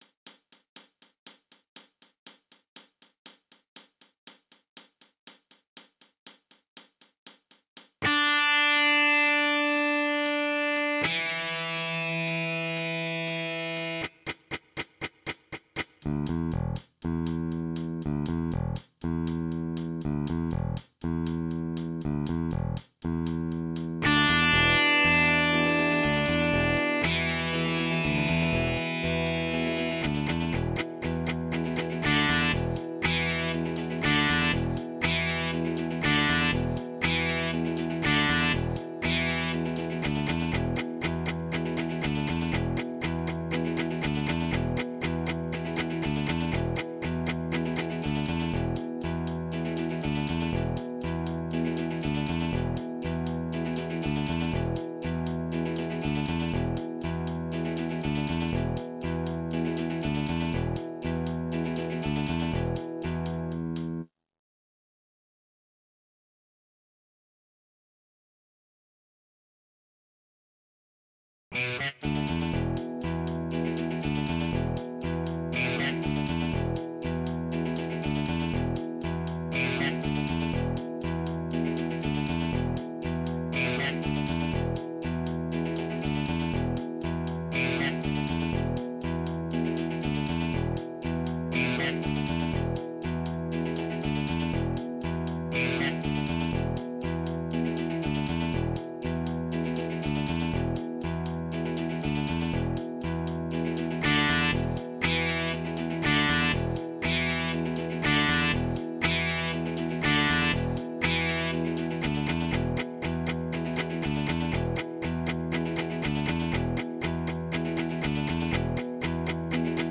El truqui es seguir al bajo, que va a piñón fijo y cada verso pilla dos compases. Los dos versos sueltos van en la parte en silencio y la cosa está en cuadrar bien el final para que encaje con la guitarra, que desde ahí subraya el final de cada verso. Cada estrofa empieza tras un chuku chuku largo de la guitarra (la parte entre paréntesis va encima del chuku chuku) y el estribillo siempre va a continuación de la parte sin música.